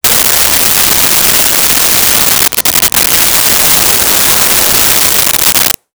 Turn Shower On 01
Turn Shower On 01.wav